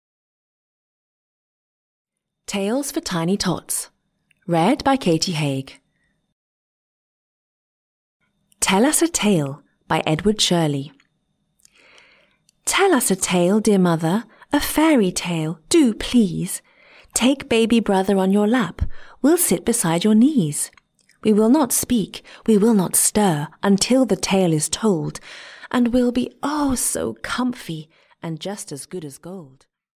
Tales for Tiny Tots (EN) audiokniha
Ukázka z knihy